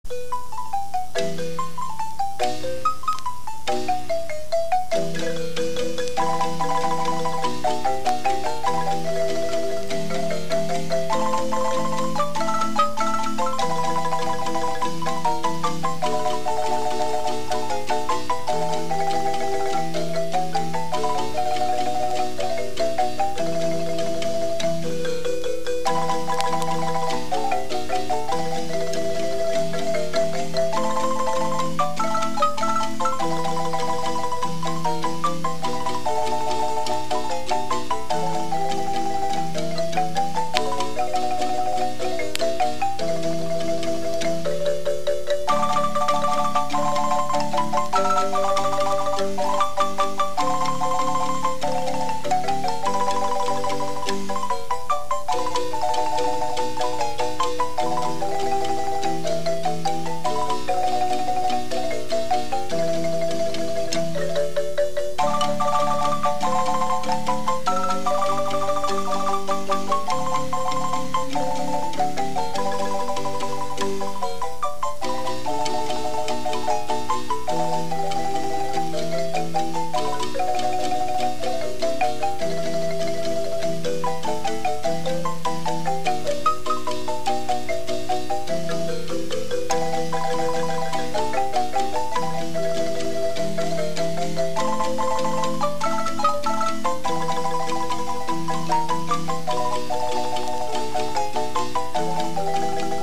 Música guanacasteca: marimba